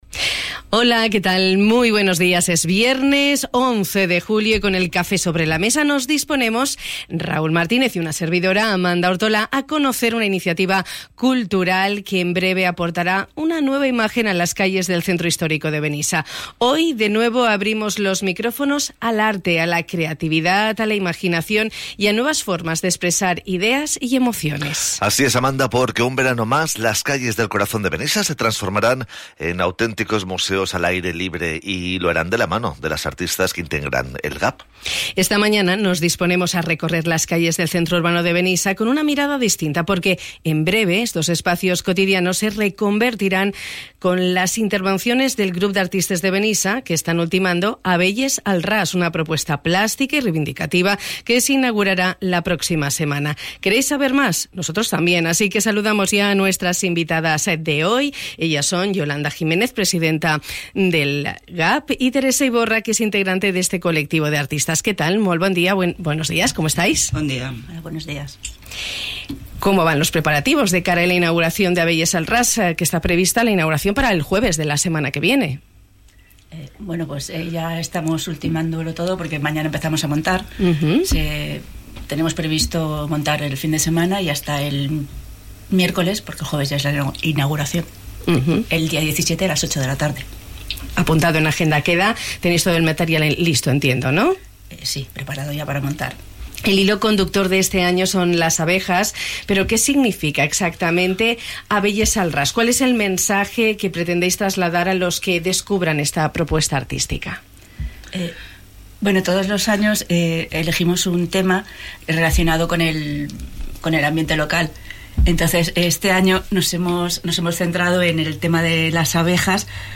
Hoy de nuevo, hemos abierto los micrófonos de Radio Litoral al arte, a la creatividad, a la imaginación y a nuevas formas de expresar ideas y emociones.